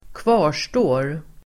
Uttal: [²kv'a:r_stå:r]